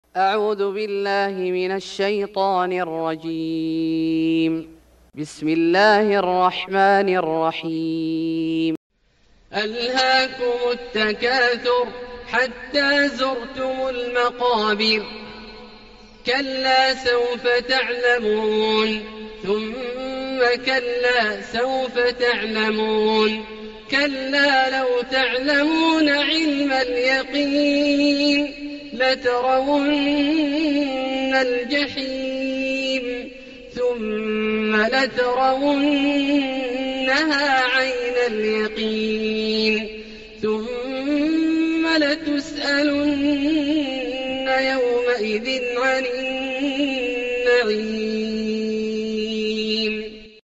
سورة التكاثر Surat At-Takathur > مصحف الشيخ عبدالله الجهني من الحرم المكي > المصحف - تلاوات الحرمين